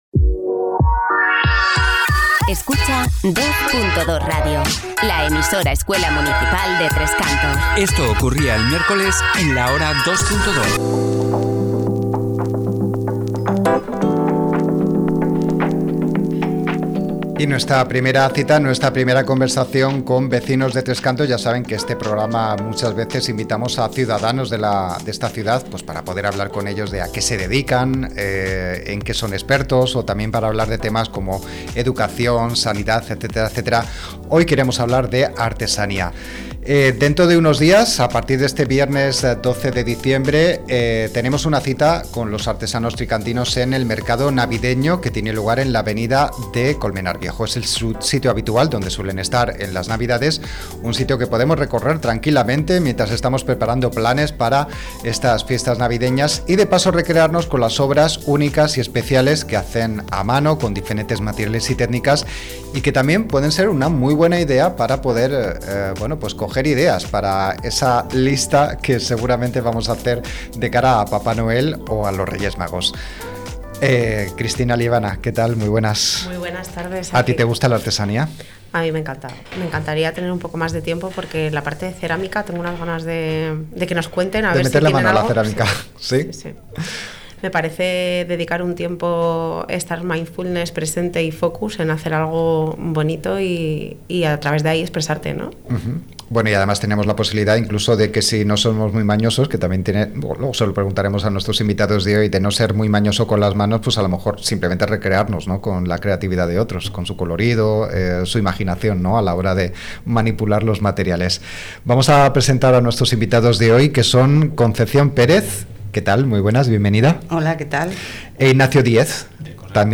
ENTREVISTA-La-artesania-el-mejor-regalo-navidenoLa-artesania-el-mejor-regalo-navideno.mp3